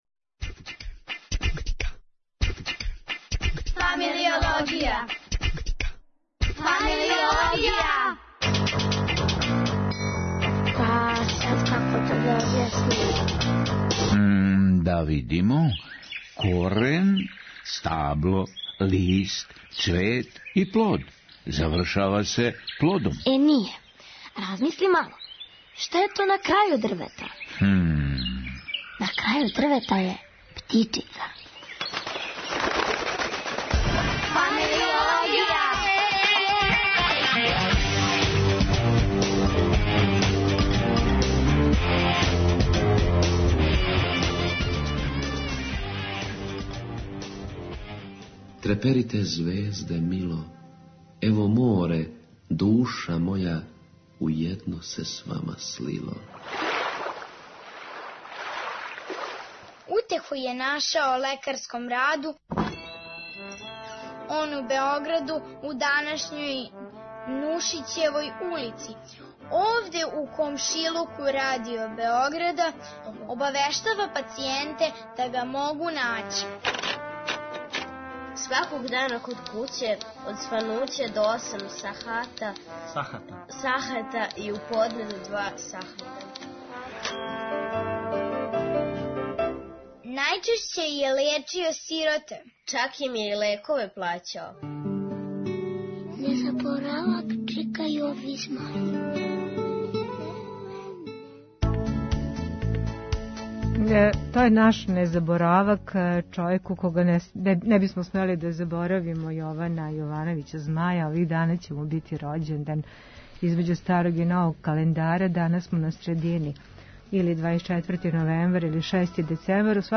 Остали млади људи, у анкети су углавном рекли да им је живот - на чекању.